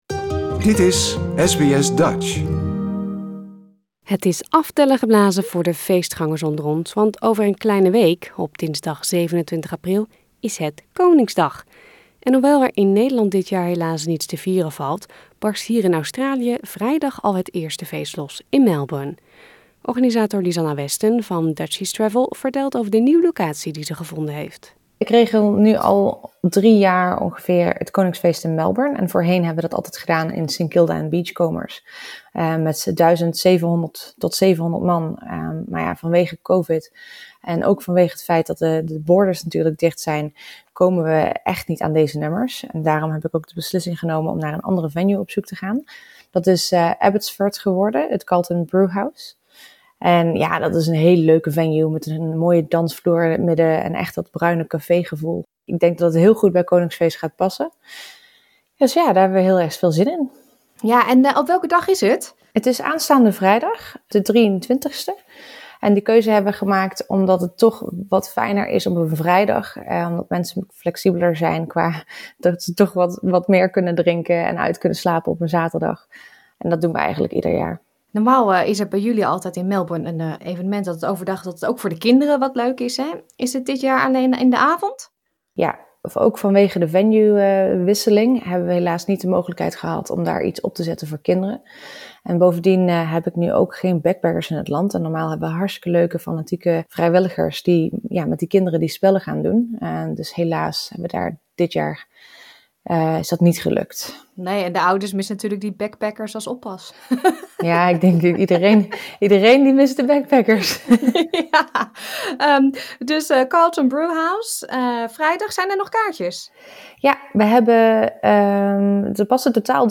Heb jij nog niets gepland voor Koningsdag? SBS Dutch belt met de organisatoren van de Koningsdagfeesten in Melbourne, Sydney, Brisbane en Perth.